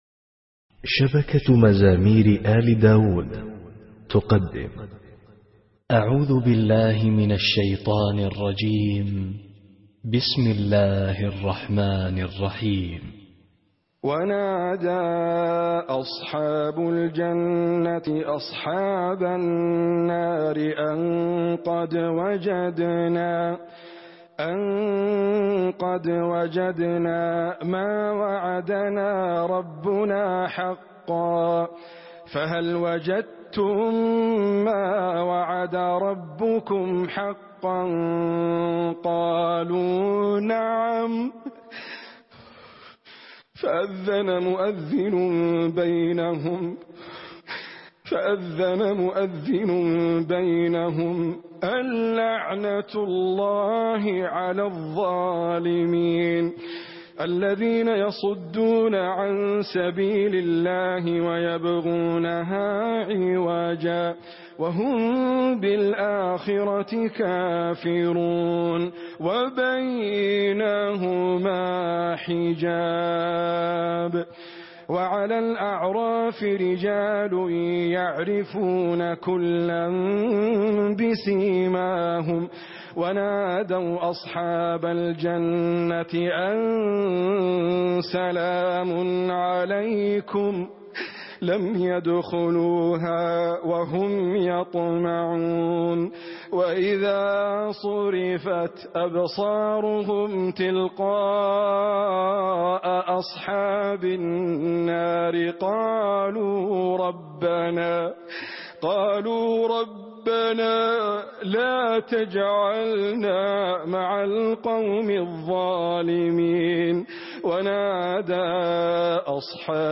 ركعة خاشعة للشيخ ادريس أبكر (الأعراف 44-51) رمضان 1434 هـ